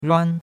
ruan1.mp3